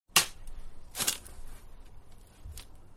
Shovel3.wav